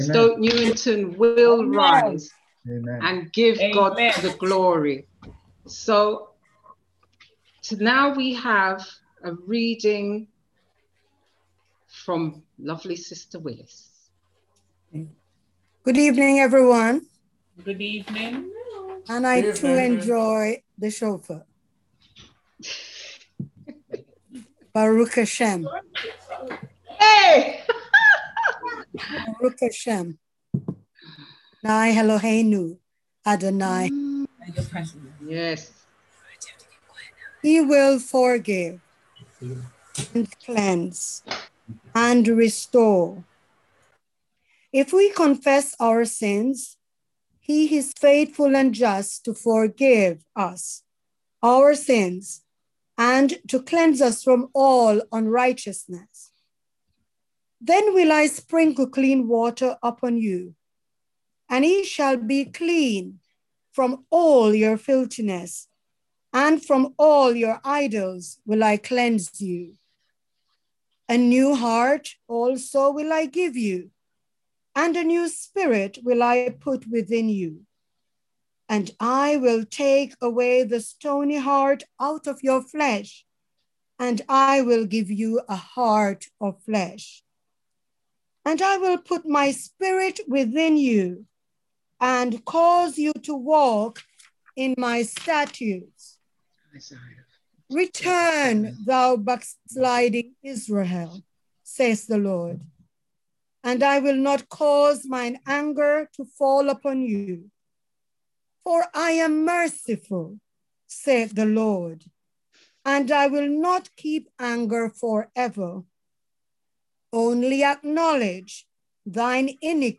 on 2021-02-08 - Redeeming Love Children & Youth Prayer Service 6.2.21